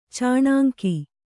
♪ cāṇāŋki